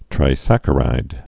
(trī-săkə-rīd, -rĭd)